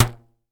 kicker_b.mp3